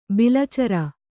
♪ bilacara